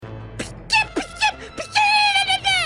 Play Pica Laugh - SoundBoardGuy
Play, download and share Pica Laugh original sound button!!!!
pica-laugh.mp3